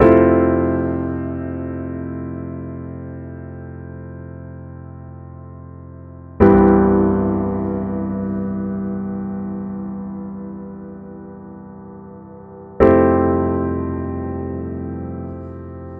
05 Piano.mp3